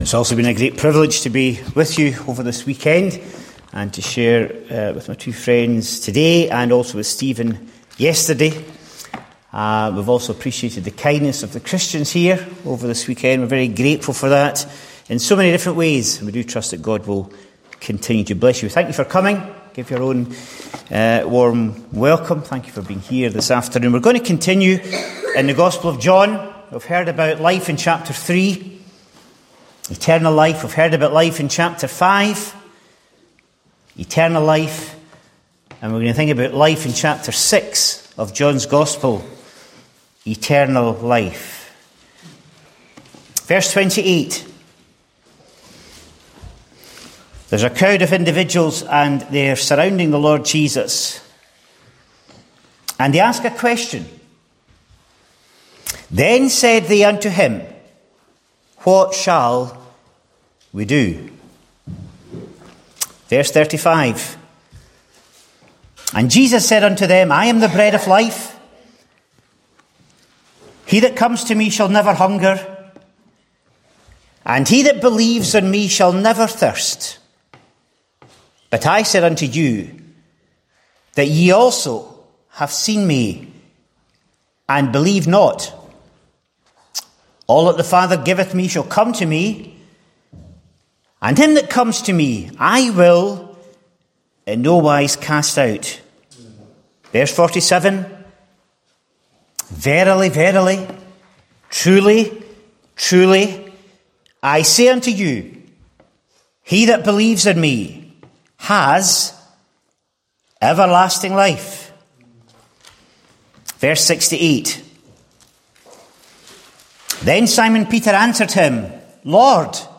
Various Gospel Messages